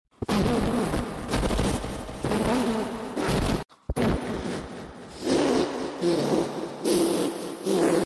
send. this to ur friends to break their ears